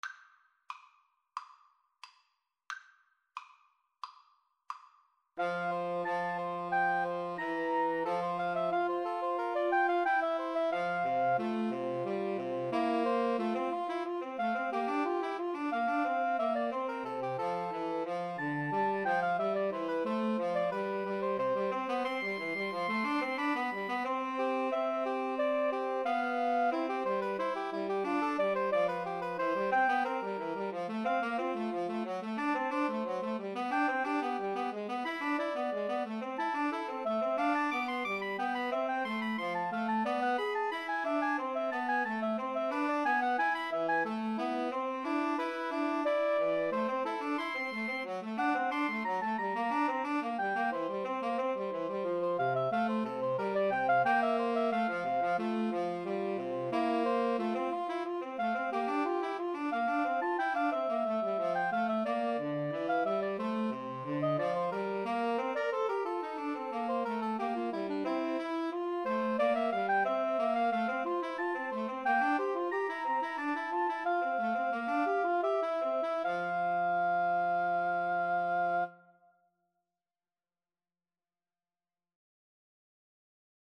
Play (or use space bar on your keyboard) Pause Music Playalong - Player 1 Accompaniment Playalong - Player 3 Accompaniment reset tempo print settings full screen
F major (Sounding Pitch) (View more F major Music for Woodwind Trio )
Woodwind Trio  (View more Advanced Woodwind Trio Music)
Classical (View more Classical Woodwind Trio Music)